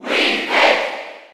Category:Crowd cheers (SSB4) You cannot overwrite this file.
Wii_Fit_Trainer_Cheer_German_SSB4.ogg